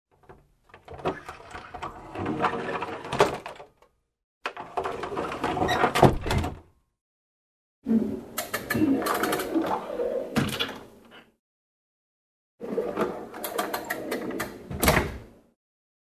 Звуки скрипа двери
Ставни открываются и закрываются